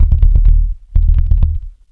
MUTEBASS  -L.wav